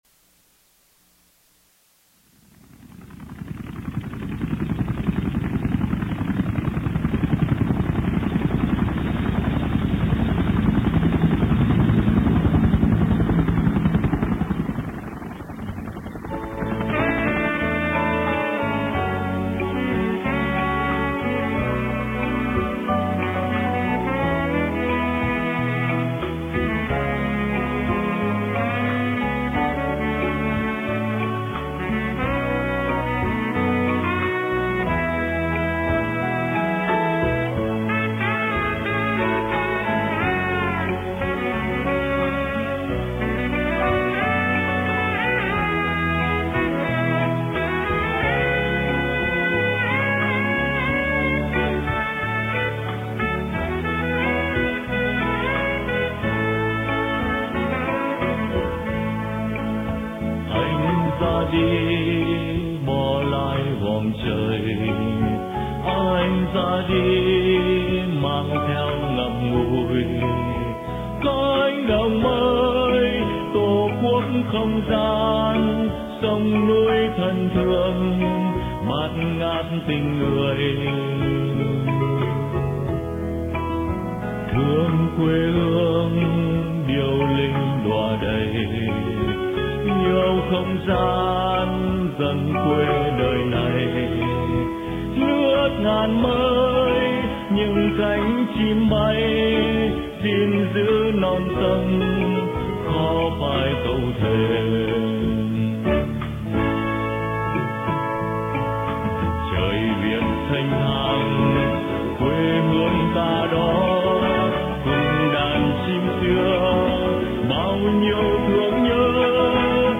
Từ cánh đồng mây - Phỏng vấn